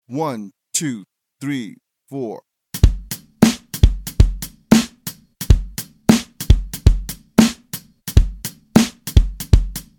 Besetzung: Instrumentalnoten für Schlagzeug/Percussion